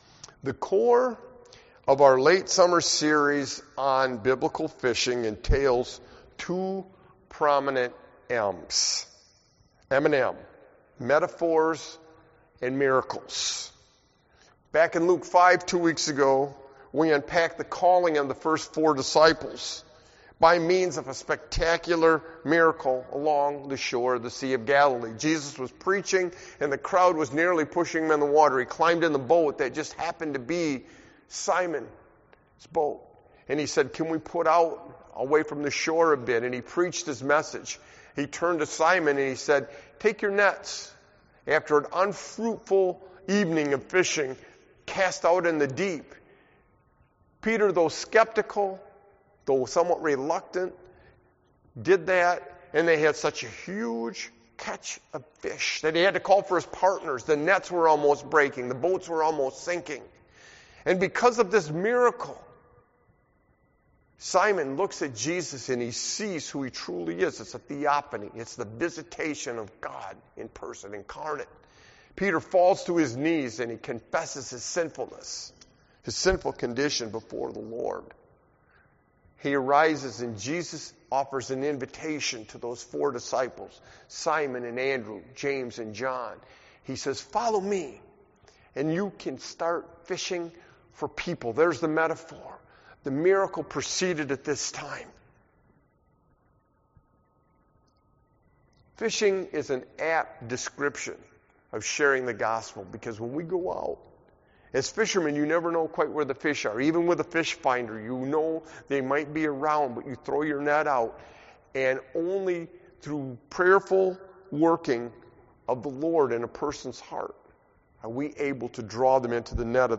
Matthew 12:38-42; Our 3rd message on the theme of biblical fishing, entailing both metaphors and miracles.